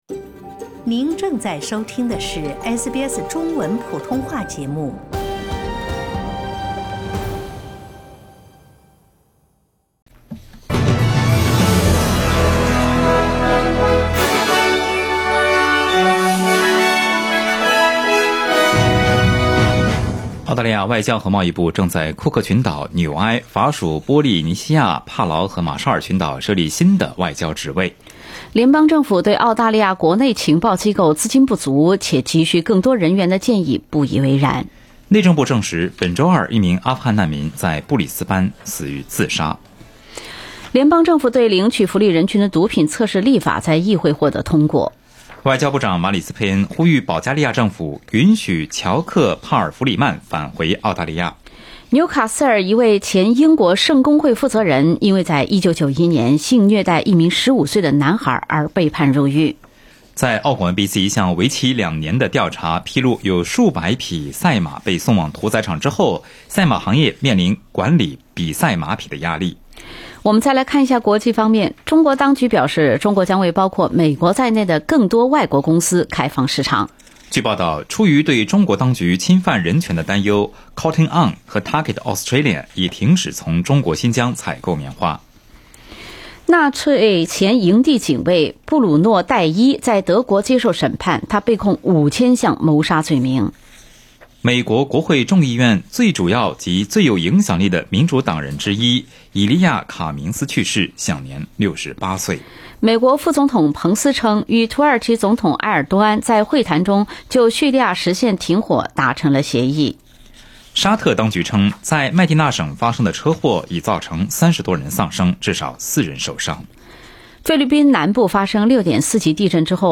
SBS早新闻（10月18日）